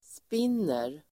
Uttal: [sp'in:er]